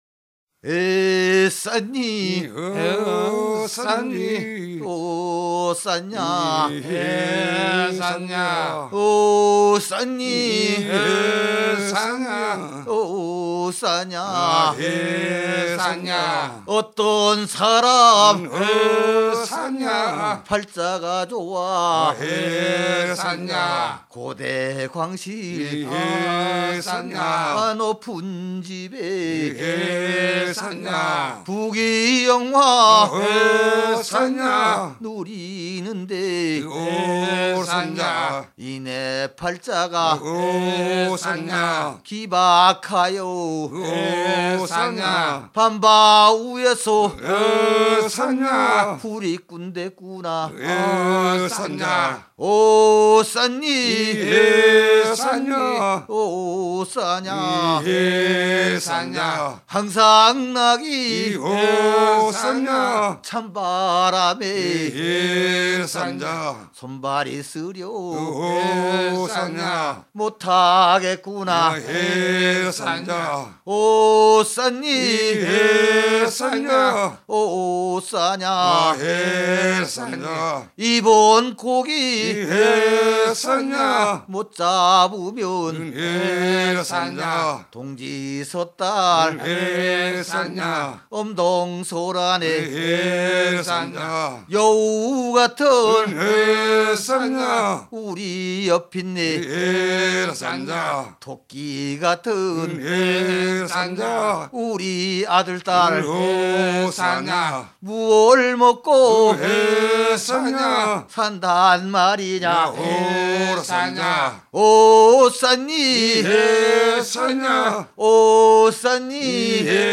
漁夫歌 - 漁業労働歌が珍しい江原道においては、江門をはじめとしてバンバウなどの漁夫歌が1枚のアルバムにまとめられたこと は江原道民謡アルバム事業の注目に値する成果である。